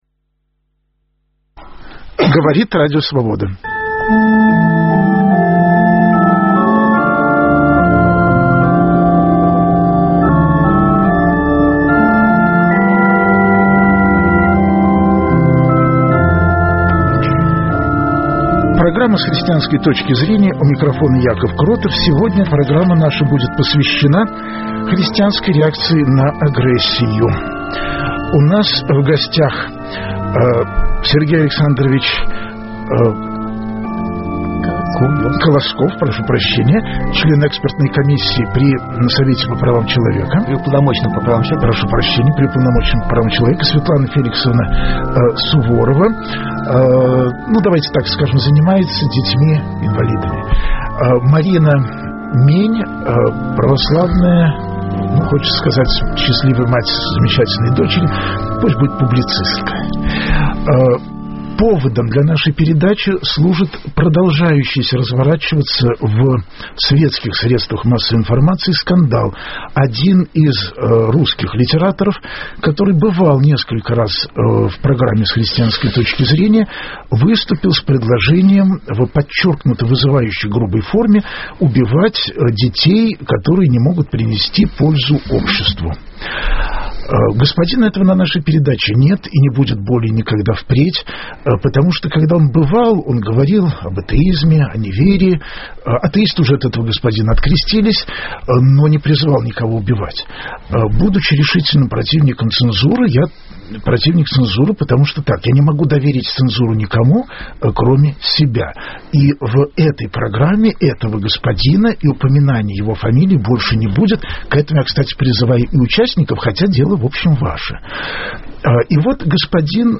Разговор с верующими родителями в прямом эфире.